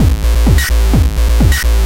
DS 128-BPM A4.wav